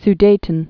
(s-dātn, z-) also Su·de·tes (s-dētēz)